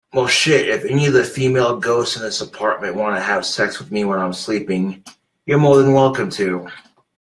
femail ghost youre more than welcom to